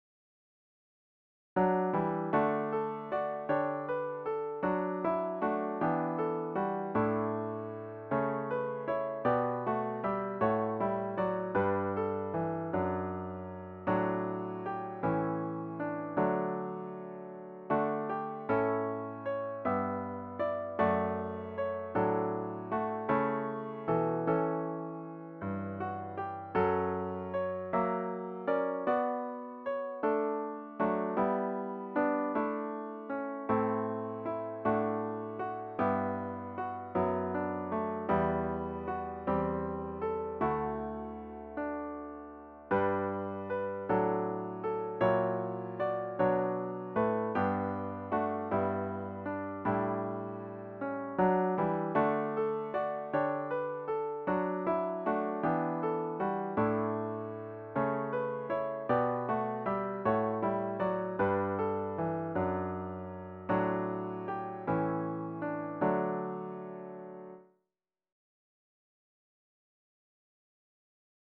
The hymn should be performed at a lilting♩. = ca. 52.